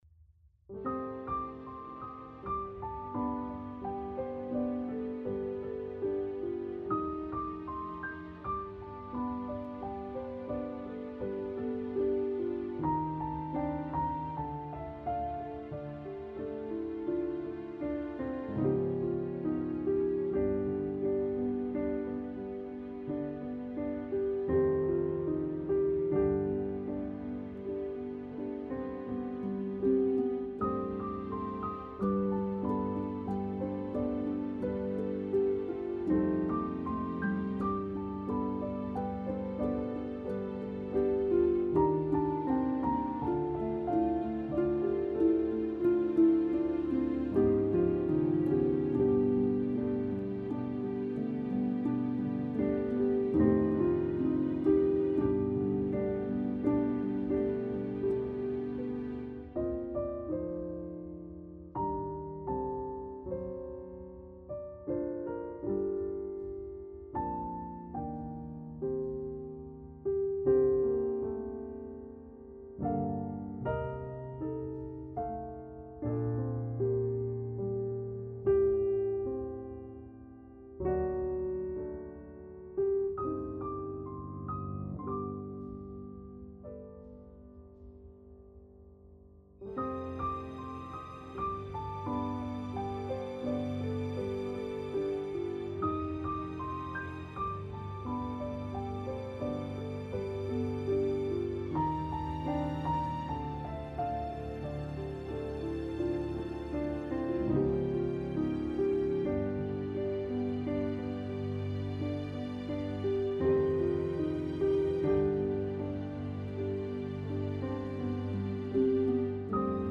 piano - calme - melodieux - melancolique - triste